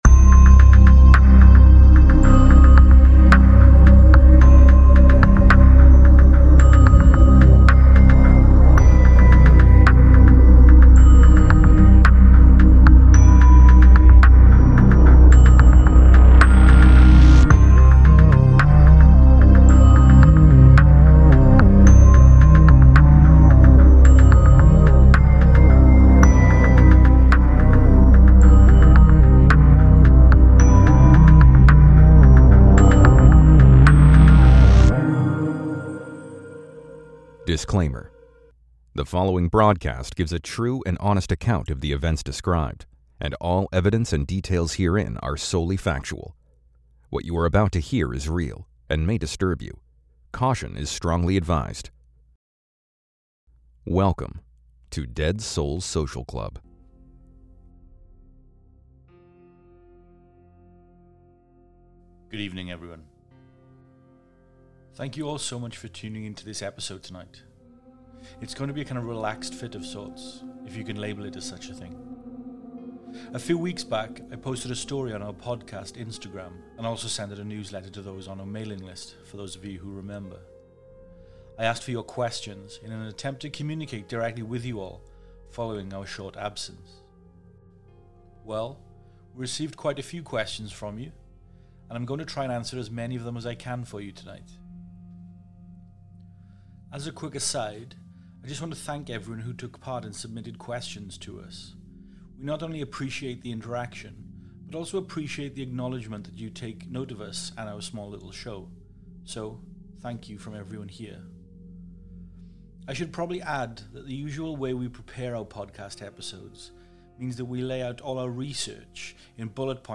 No advance prep
We take the time to answer some of your questions tonight. Off the cuff and vey real.